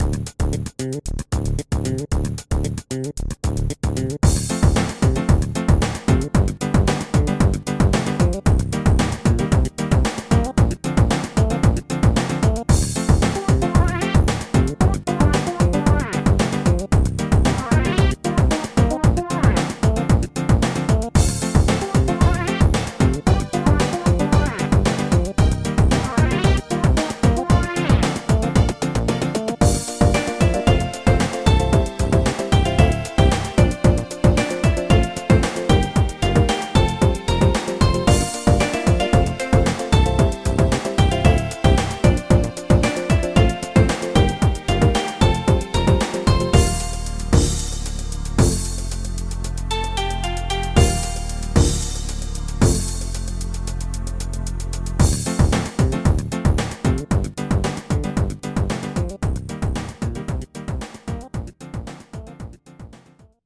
bass reflux